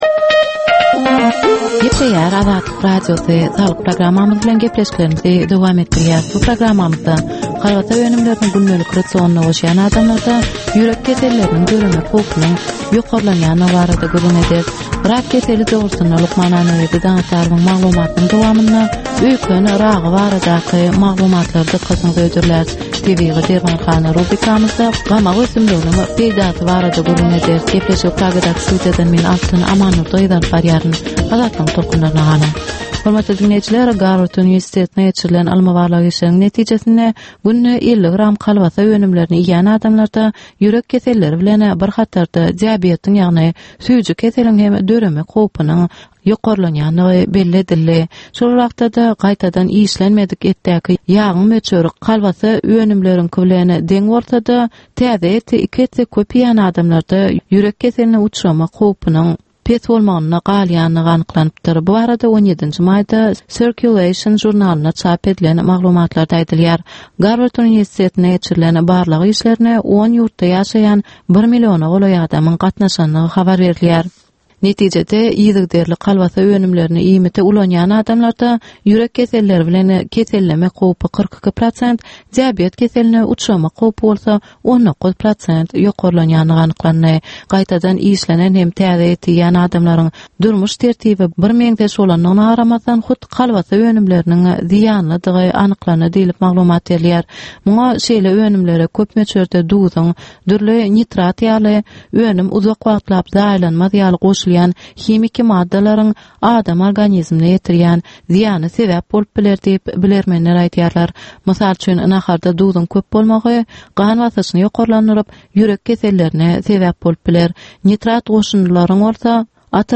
Ynsan saglygyny gorap saklamak bilen baglanyşykly maglumatlar, täzelikler, wakalar, meseleler, problemalar we çözgütler barada 10 minutlyk ýörite gepleşik.